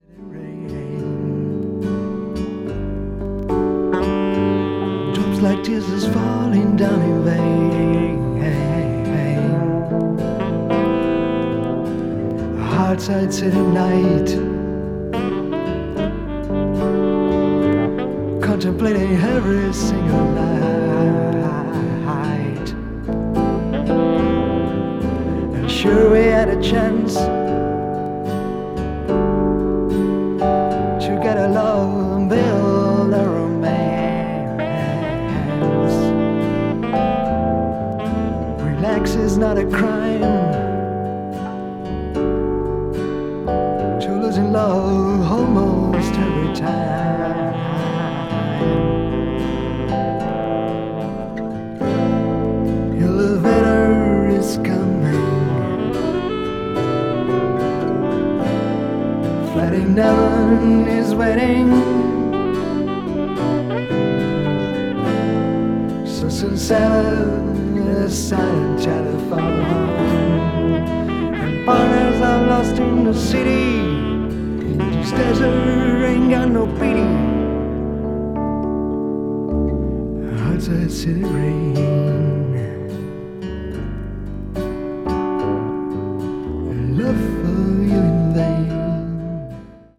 mellow sound of acoustic guitar and electric piano
weathered vocals
a.o.r.   blues rock   country rock   folk rock